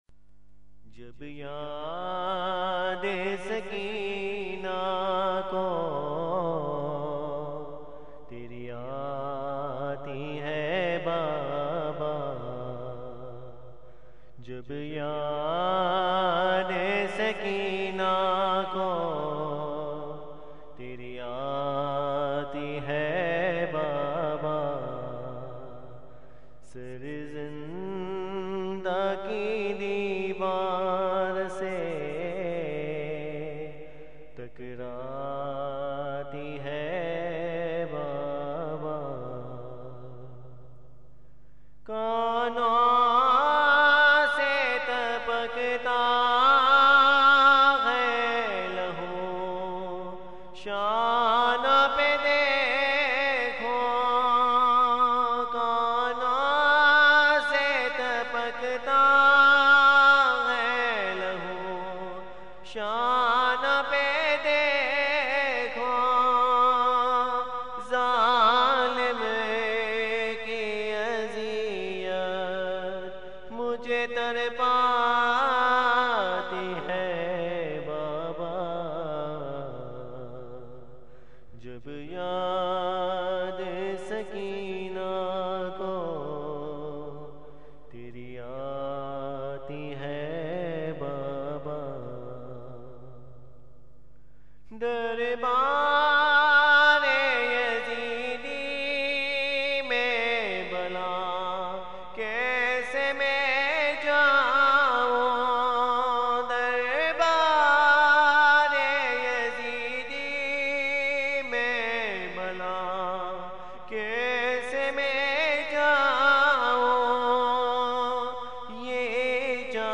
سوزوسلام اورمرثیے